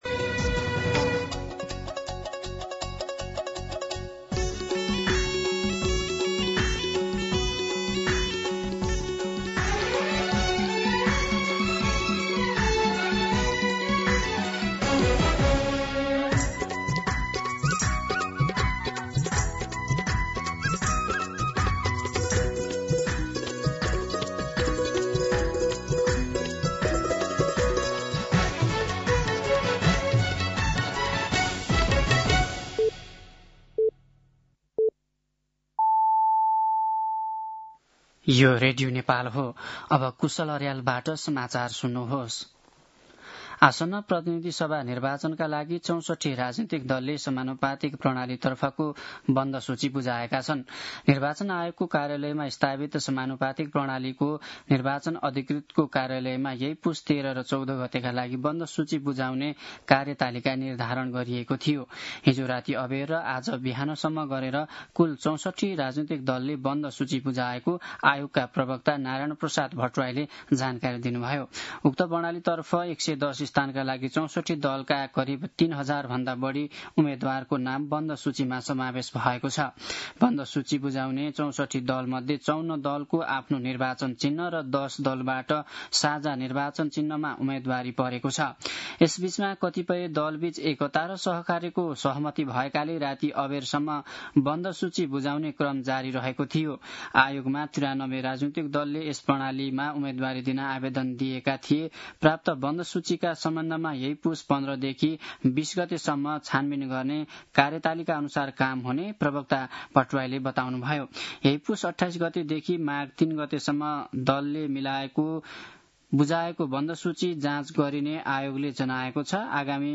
दिउँसो १ बजेको नेपाली समाचार : १५ पुष , २०८२